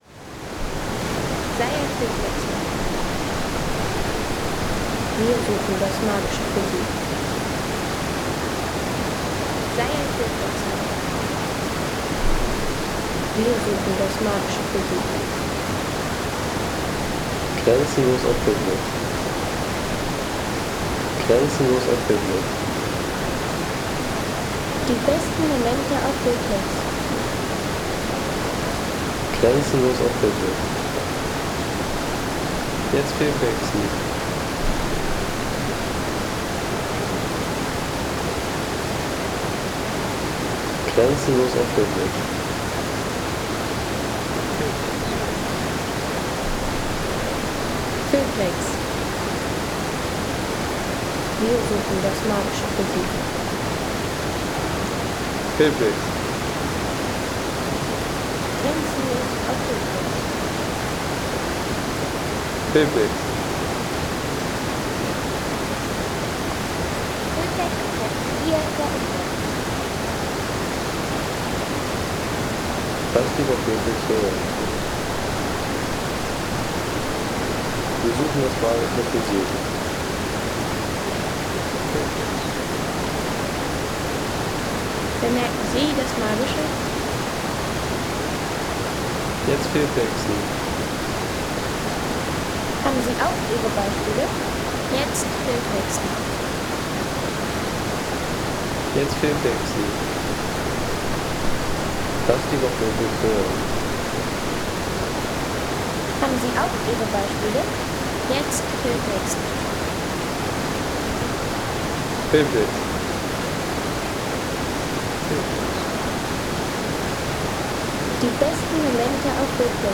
Landschaft - Bäche/Seen
Die Gföller Mühle am Themenwanderweg 'Wilde Wasser'.